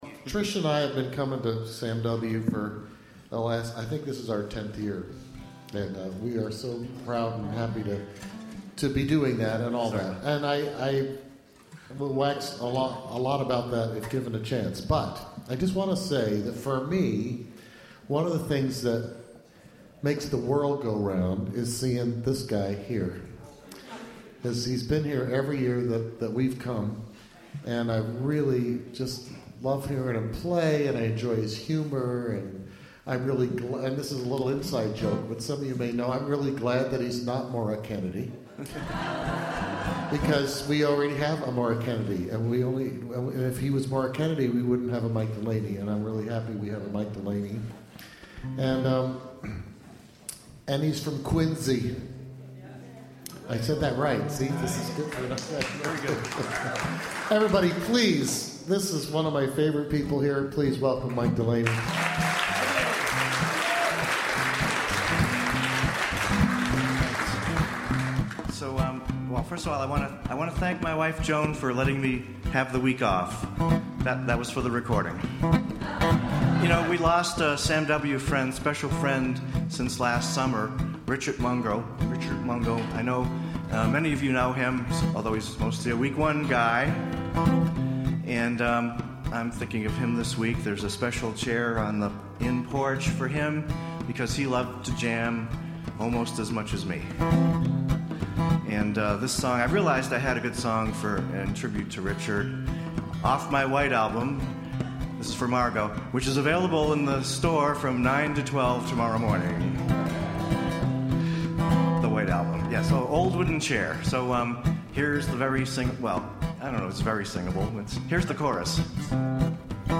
student concert performance